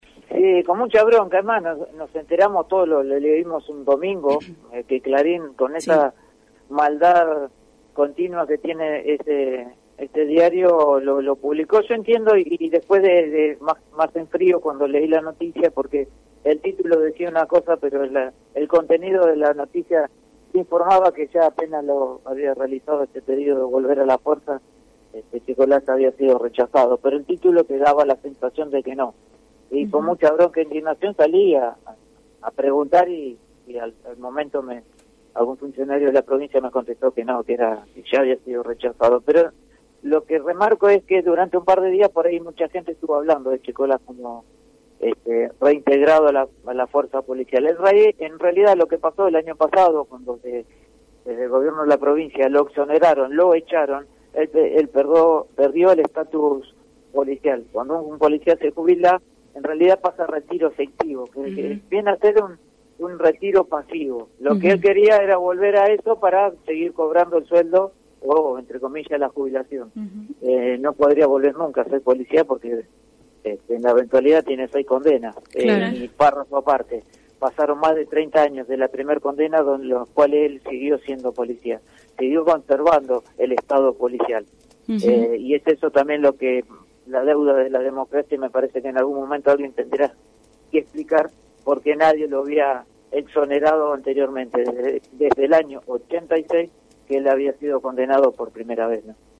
En diálogo con Caídas del Catre